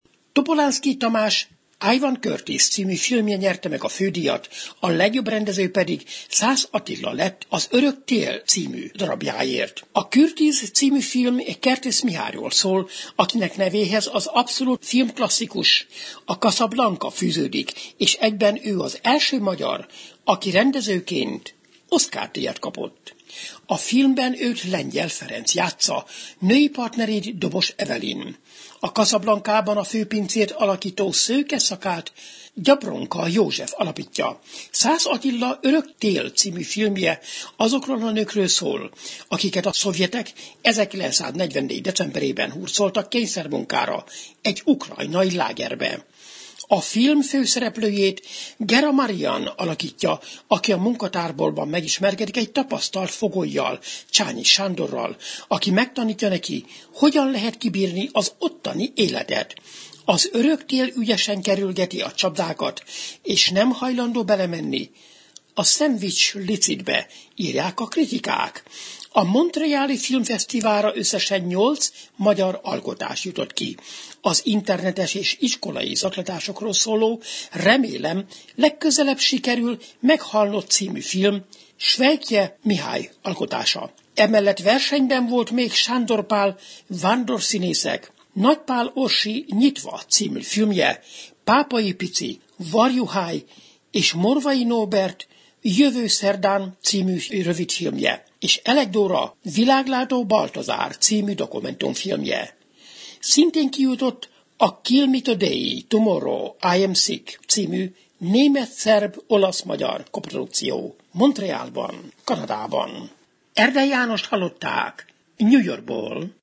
New York-i tudósítása.